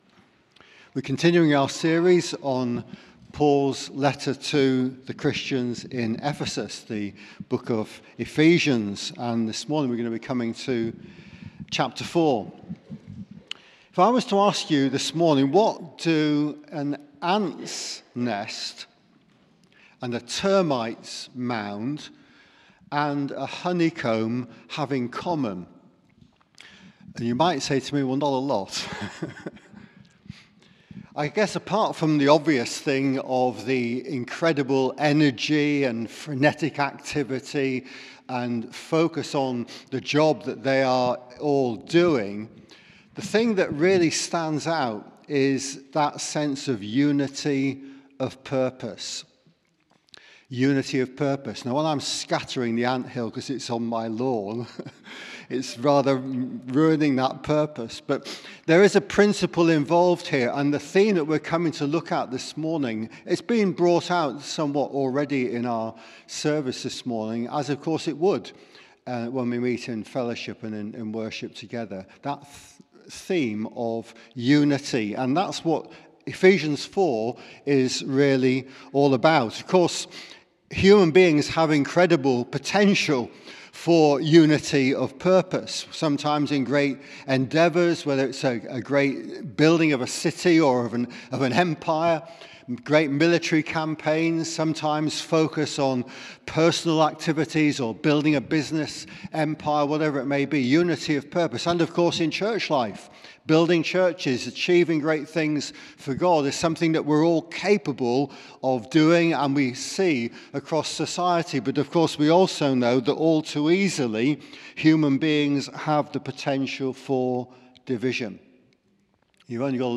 Sermon - Ephesians 4: 1-16